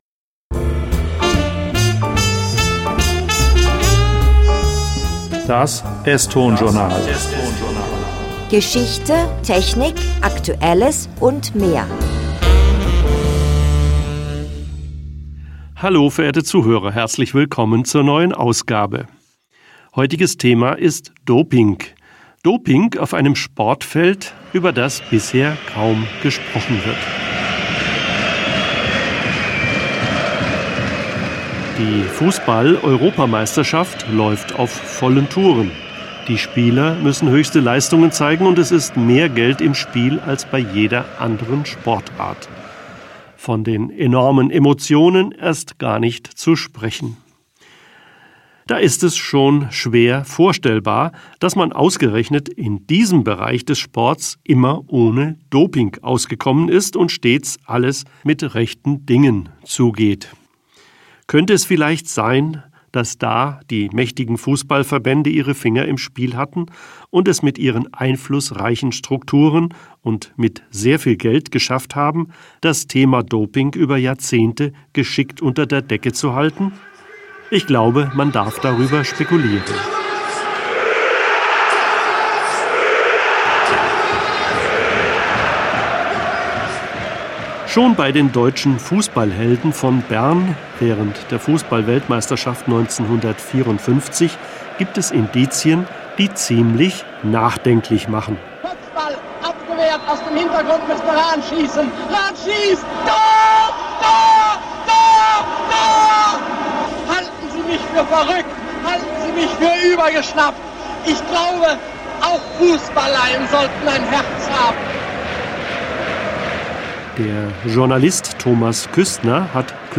Effekte und technische Realisierung